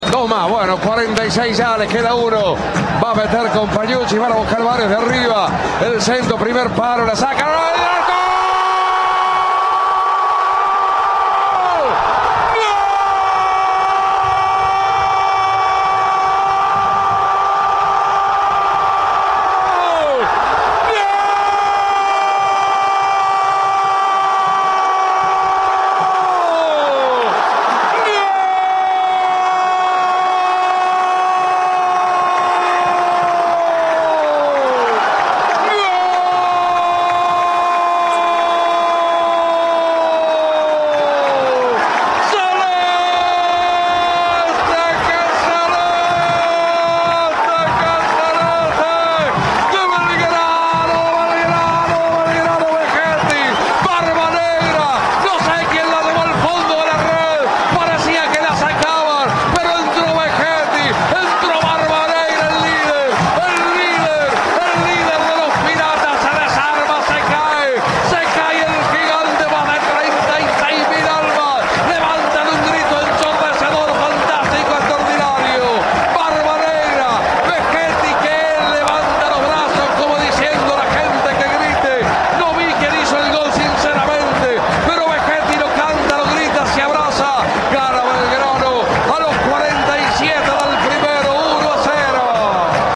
goles, relato